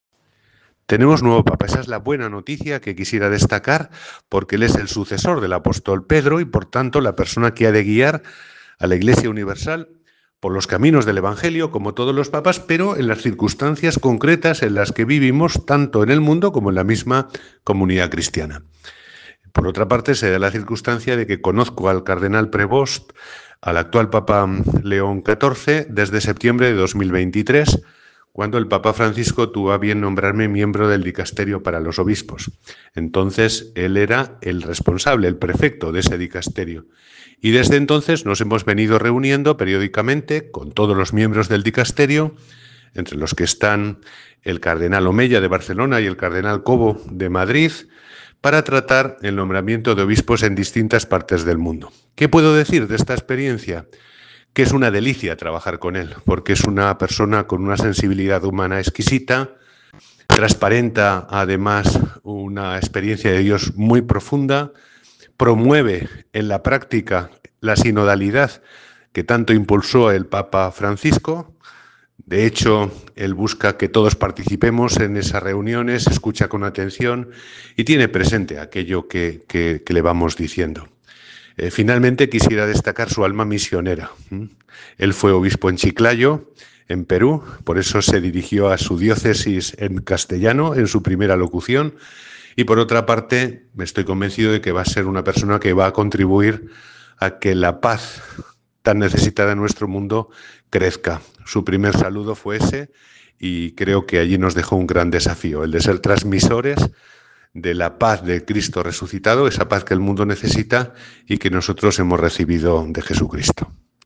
En el día de hoy, 9 de mayo, don José Antonio ha mandado un nuevo audio en el que nos acerca a la figura del nuevo Papa León XIV.